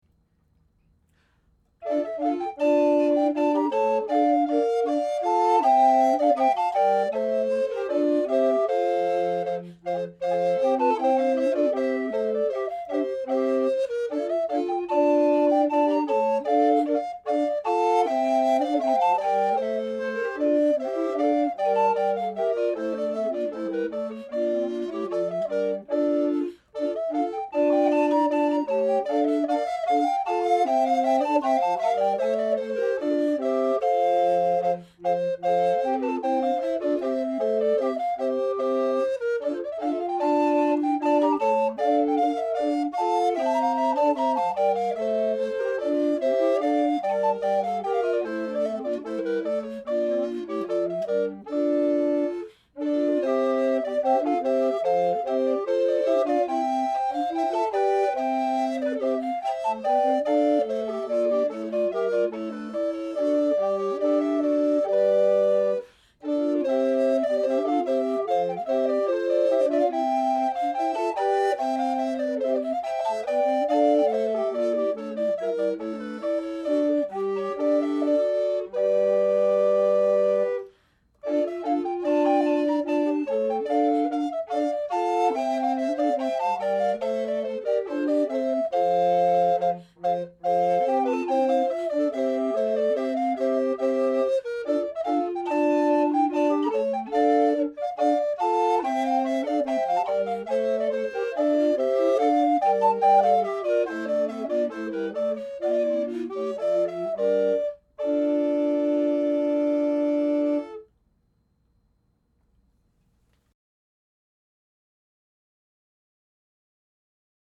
Music from the 15th and 16th centuries
recorders
at the Loring-Greenough House, Jamaica Plain